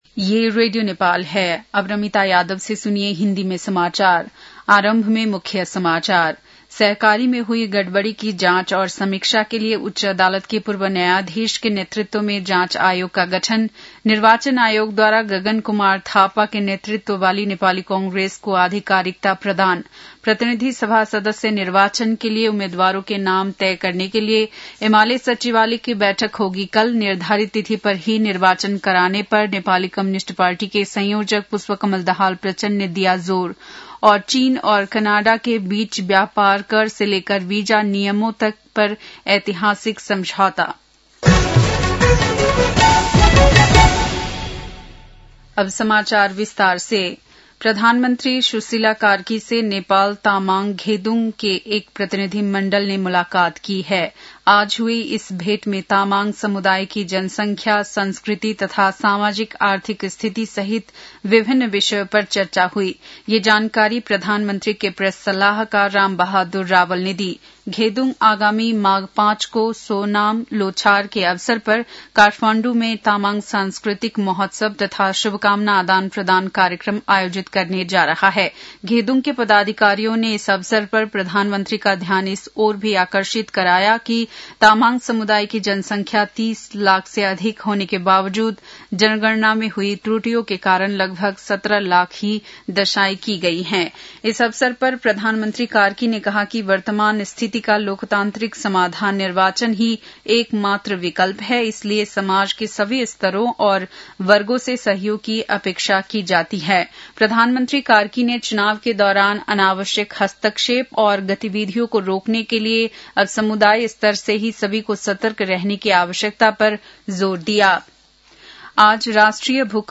बेलुकी १० बजेको हिन्दी समाचार : २ माघ , २०८२